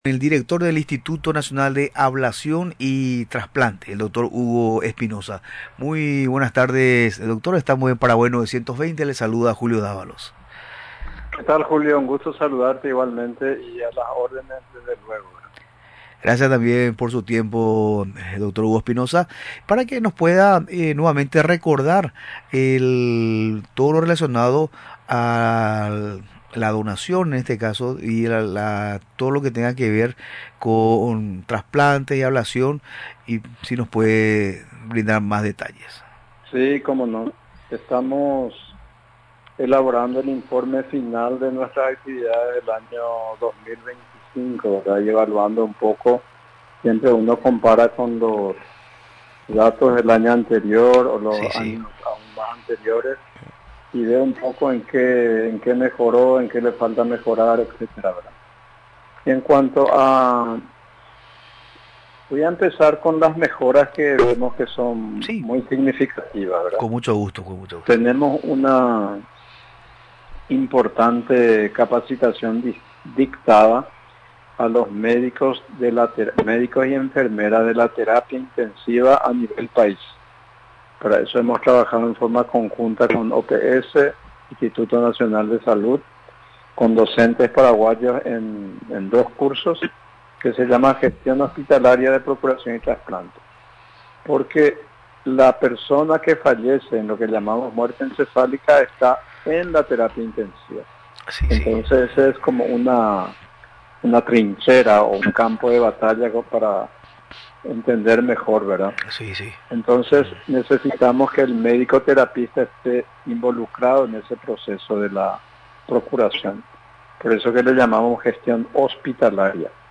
Durante la entrevista en Radio Nacional del Paraguay, resaltó las obras y la tecnología introducía en la institución a su cargo durante el 2025.